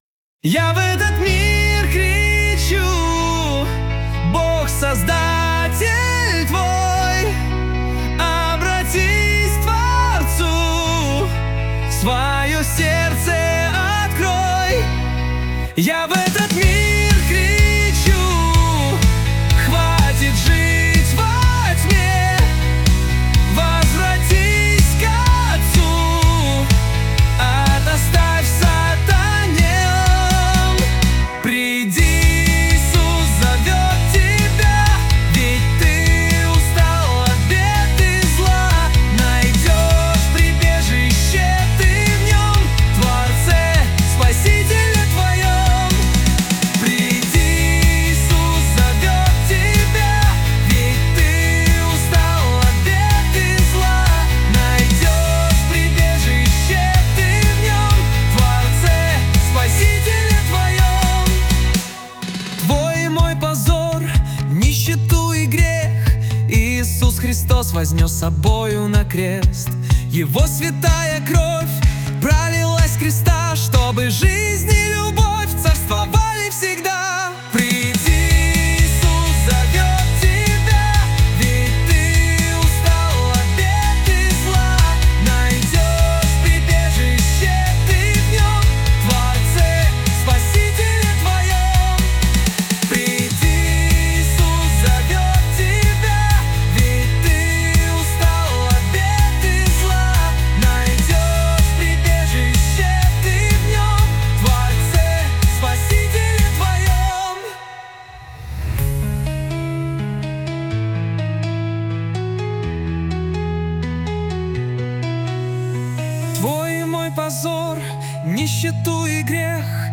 песня ai
168 просмотров 916 прослушиваний 47 скачиваний BPM: 125